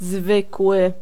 Ääntäminen
IPA: /a.bit.ɥɛl/